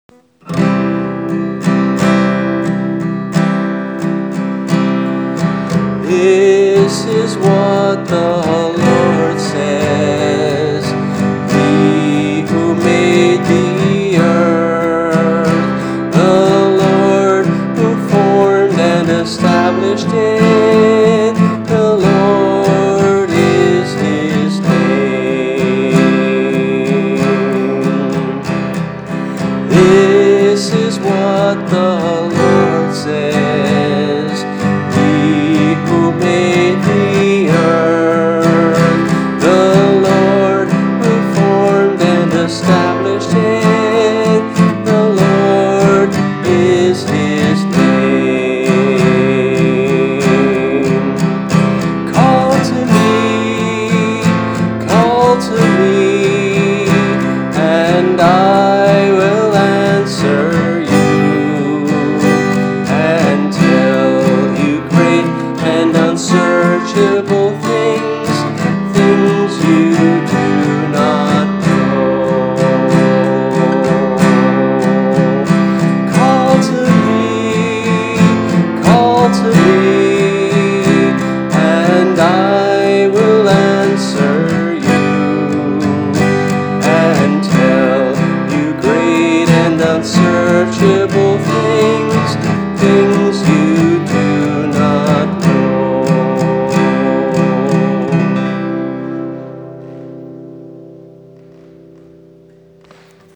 voice and guitar